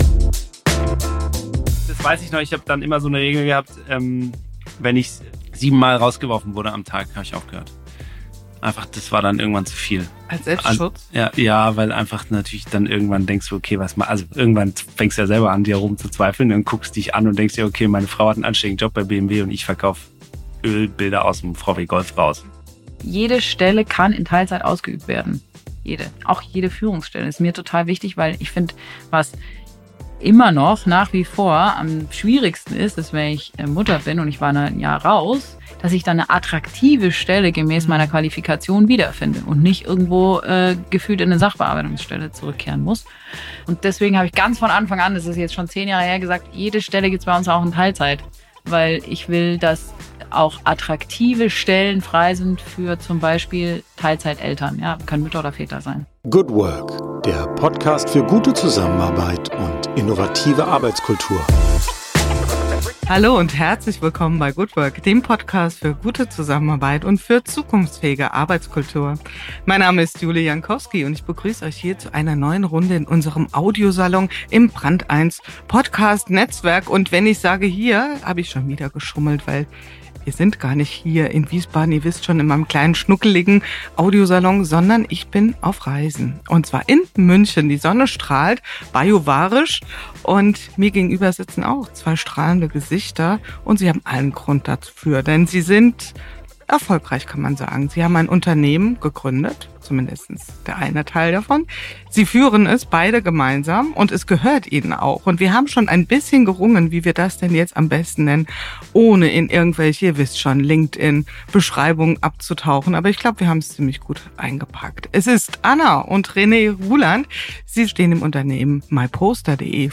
Wie baut man eine robuste, menschenzentrierte Arbeitskultur? Ein Gespräch über Klarheit, Rollen, Struktur und die Frage, wie gutes Unternehmertum gelingen kann.